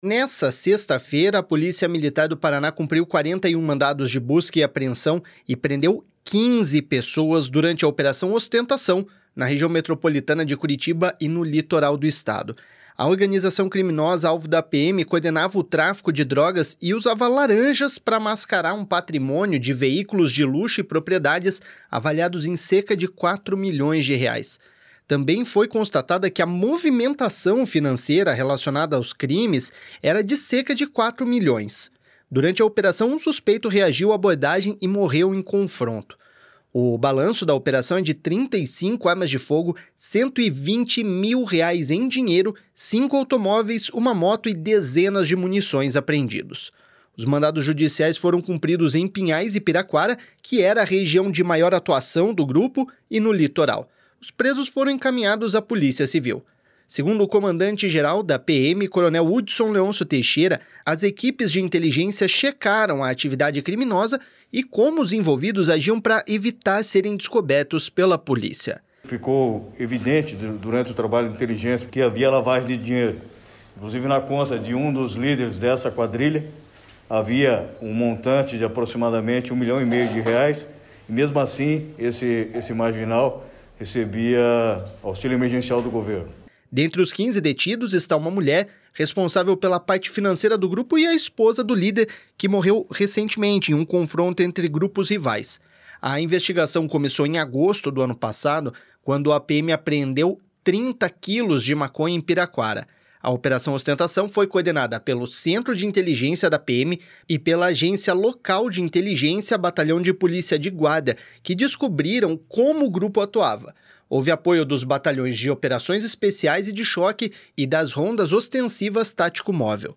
Segundo o comandante-geral da PM, coronel Hudson Leôncio Teixeira, as equipes de inteligência checaram a atividade criminosa e como os envolvidos agiam para evitar serem descobertos pela polícia.// SONORA CORONEL HUDSON.//